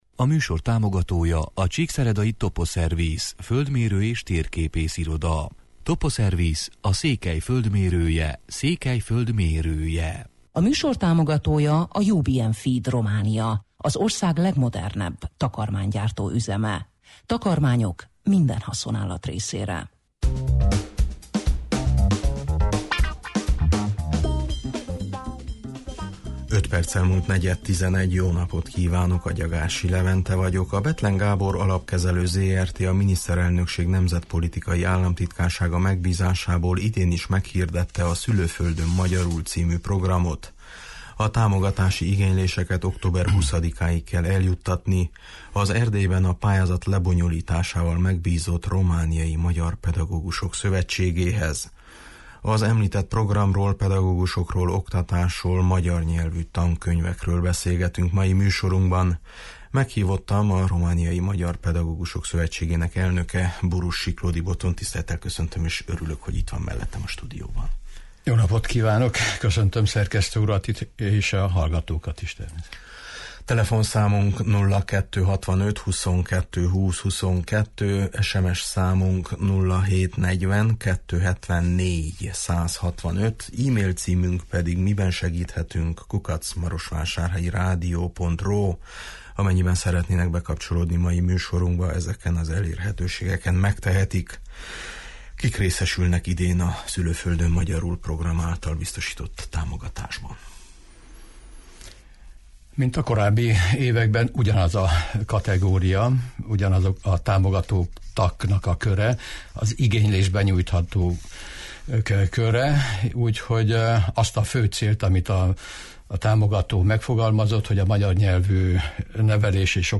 Az említett programról, pedagógusokról, oktatásról, magyar nyelvű tankönyvekről beszélgetünk mai műsorunkban.